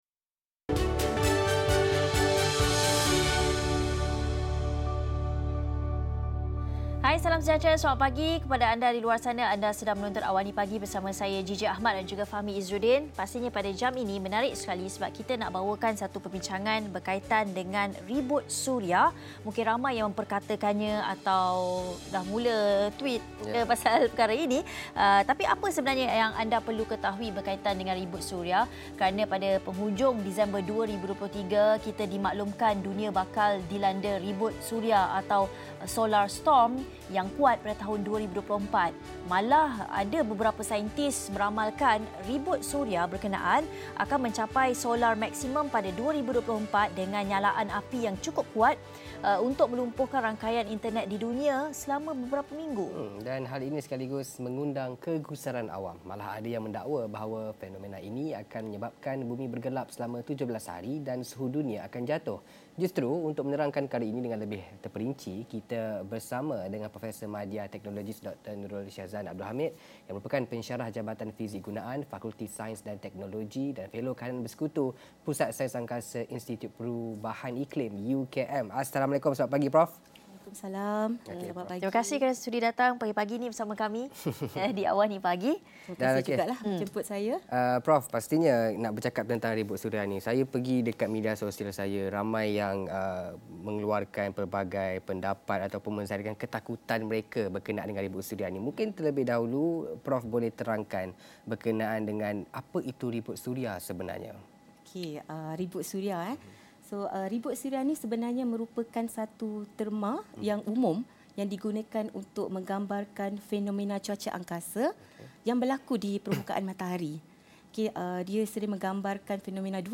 Diskusi